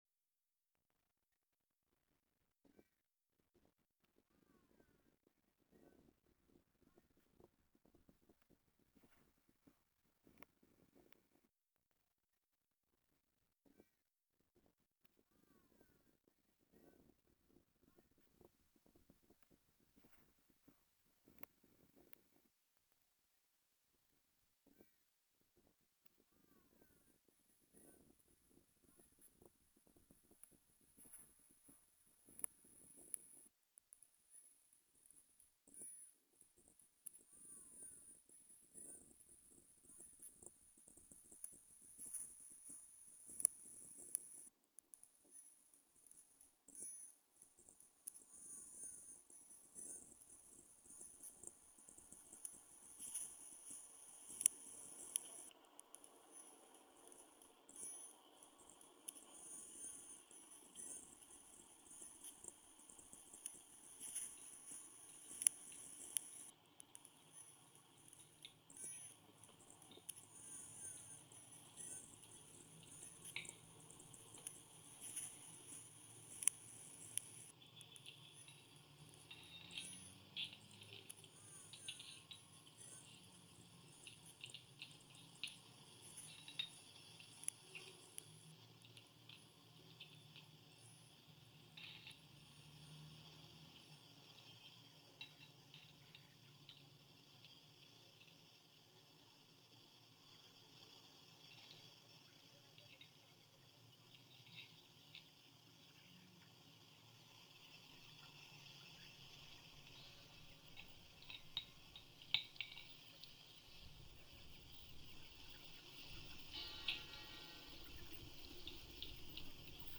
Sound art piece, selected by Transónica to be presented during the In-Sonora sound art festival in Madrid, Spain.